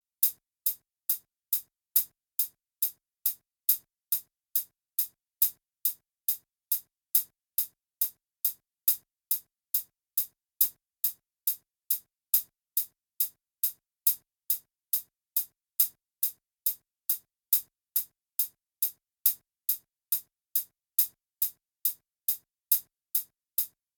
Minus All Guitars Pop (2010s) 3:49 Buy £1.50